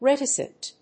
/réṭəsnt(米国英語), ˈretɪsʌnt(英国英語)/